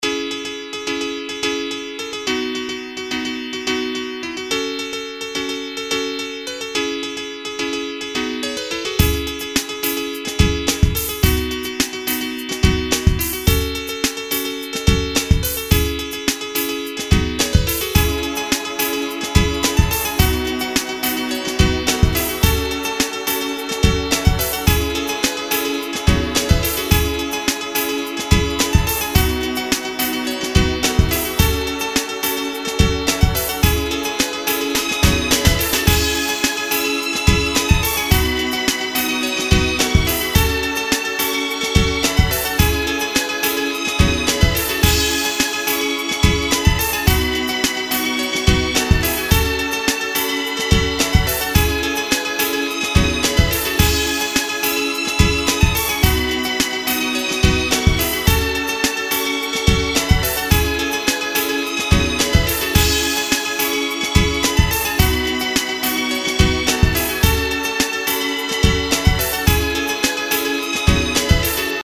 Vakti zamanında Fast Tracker'ın son dönemlerinde yardırdığım şarkılardan biri. Buyrunuz piyano nasıl çalınır şahit olunuz :) Ayrıca bateri de çok iyi olmuş be hacı.